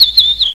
OISEAU2.WAV